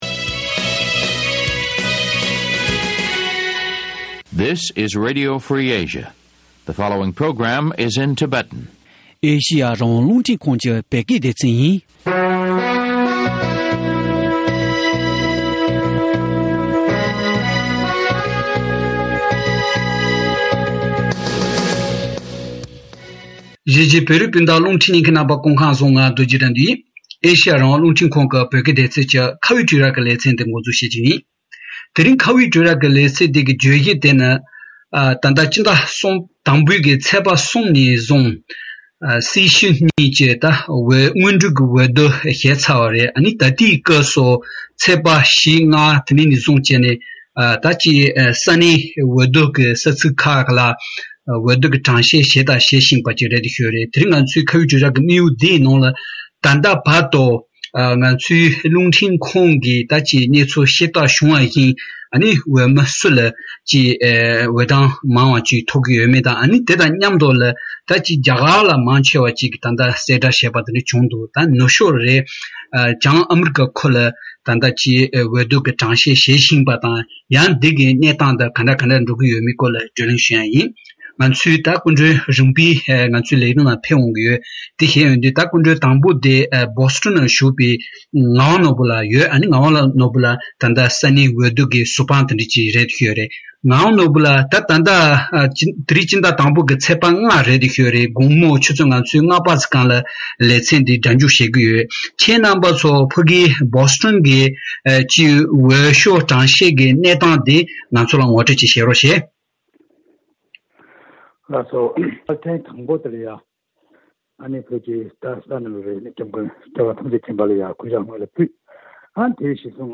ཕྱི་ཟླ་༡ཚེས་༣ཉིན་བཙན་བྱོལ་བོད་མིའི་ཁྲོད་༢༠༢༡ལོའི་སྲིད་སྤྱིའི་འོས་བསྡུ་དང་འབྲེལ་བའི་སྐོར་བགྲོ་གླེང་ཞུས་པ།